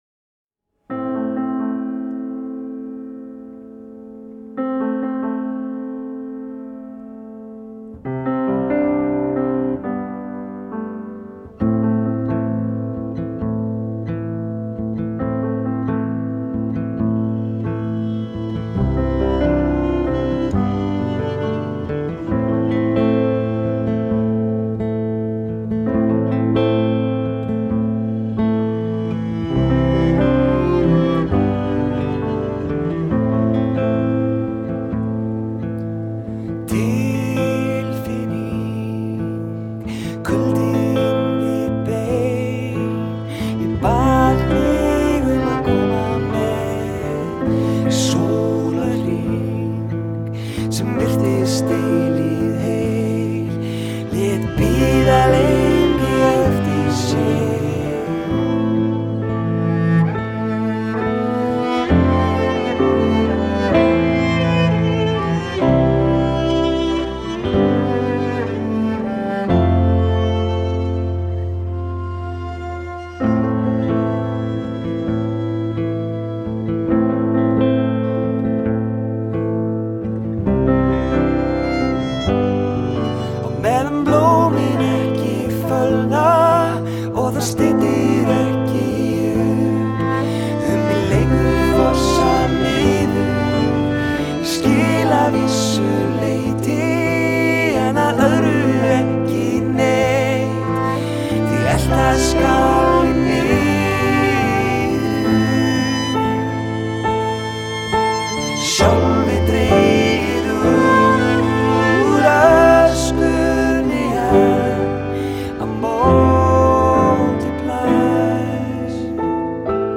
beautiful melody